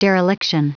Prononciation du mot dereliction en anglais (fichier audio)
Prononciation du mot : dereliction